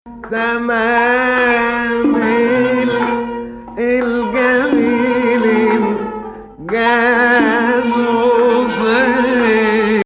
2nd Saba 6/8